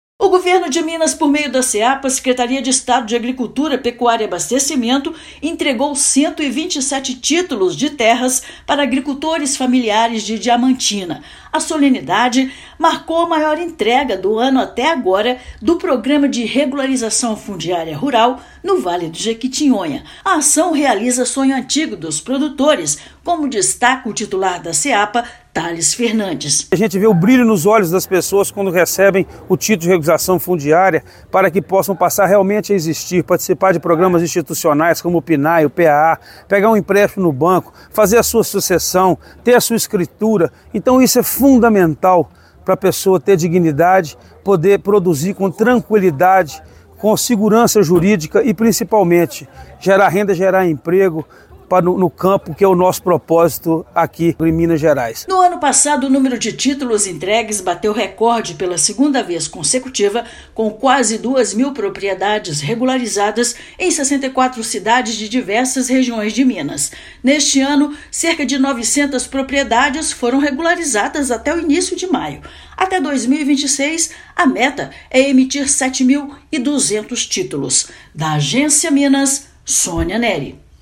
Ação é a maior do ano e significa a realização de sonhos para população; total de propriedades regularizadas até maio já representa metade da meta do Estado para 2024. Ouça matéria de rádio.